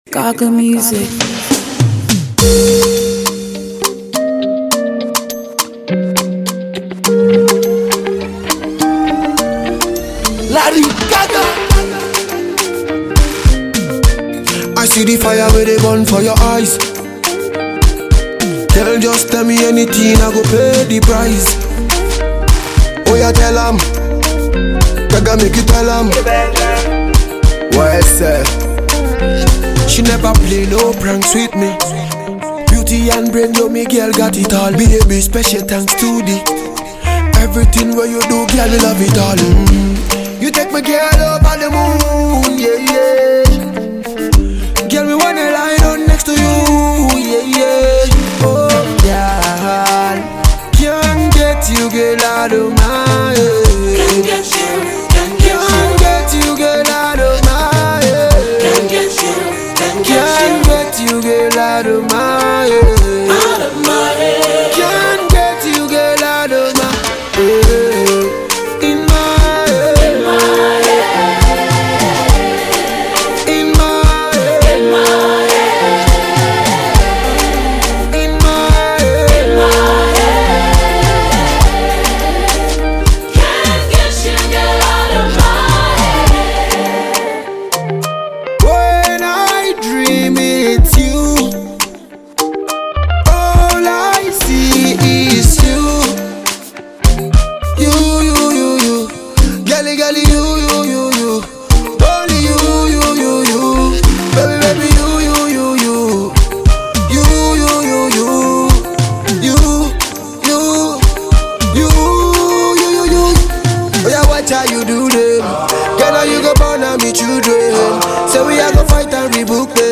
featuring Dance Hall king